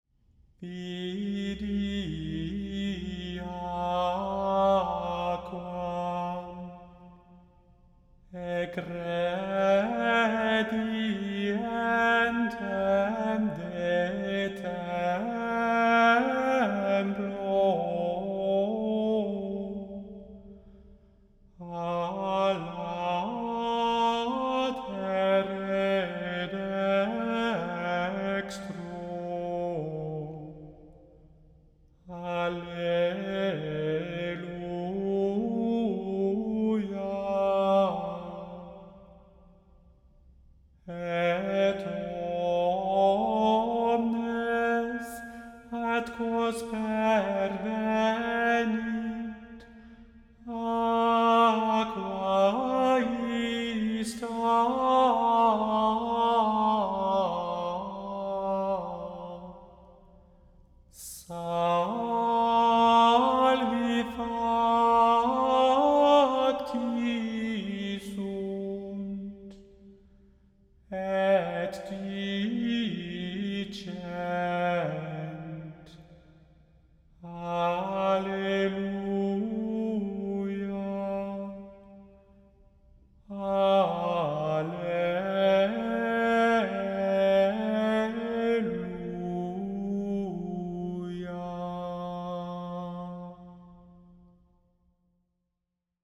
The Chant Project – Chant for Today (July 4) – Vidi aquam